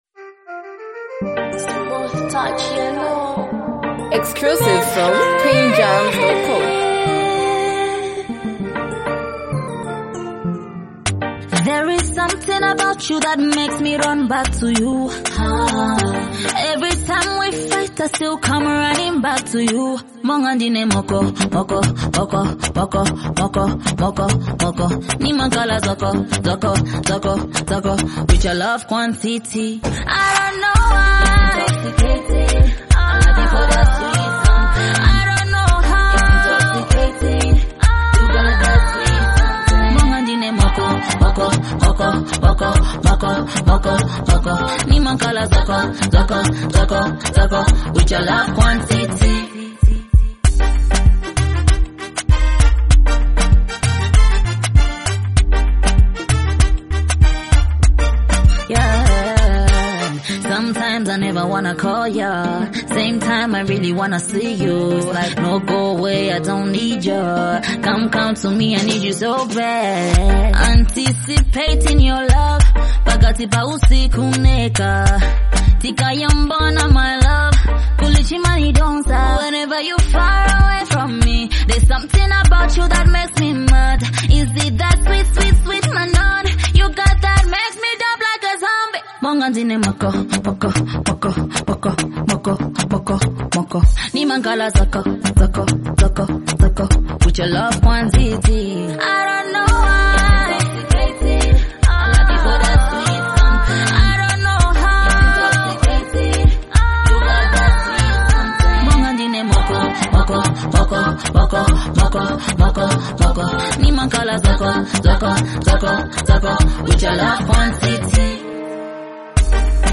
a bold, energetic track
club-friendly anthem